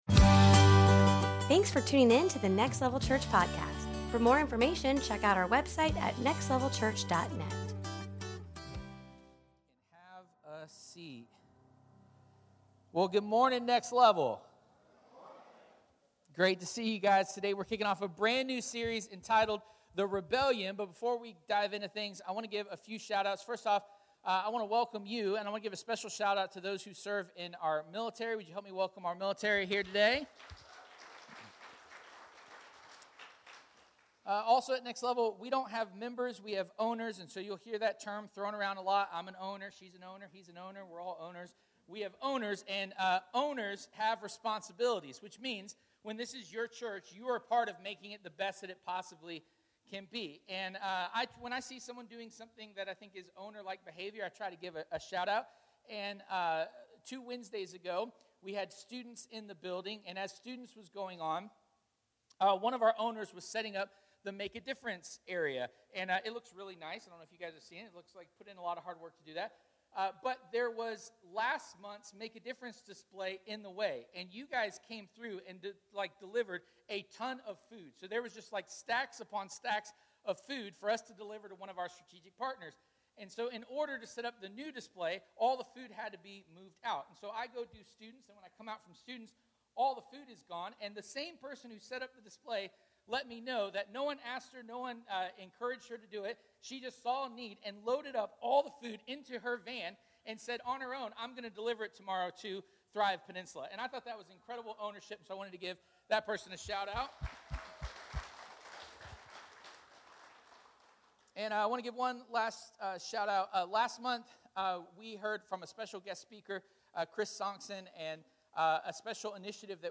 The Rebellion Service Type: Sunday Morning We hear often in church circles that humans are broken.
9-10-23-Sermon.mp3